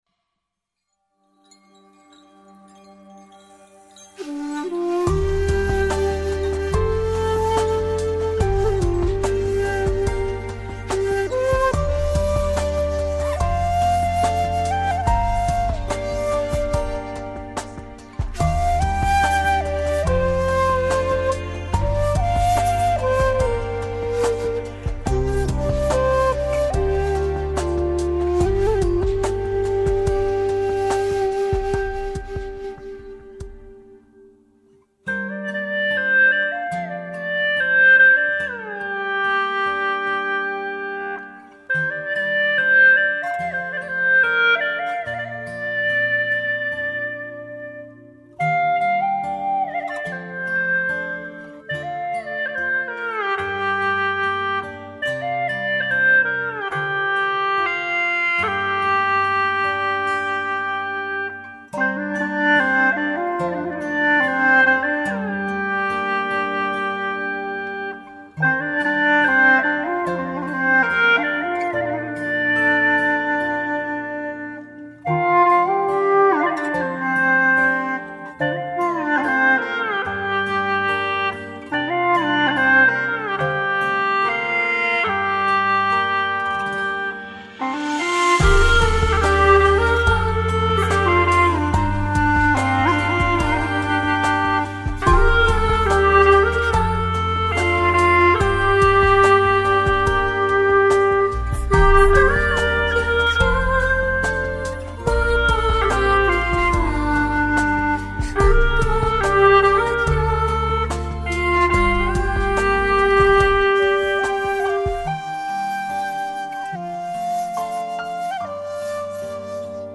调式 : 降B 曲类 : 古风
风铃声、古琴声、葫芦丝声,淡淡的忧伤如月光般洒落下来,诉说着、回忆着,触动人心……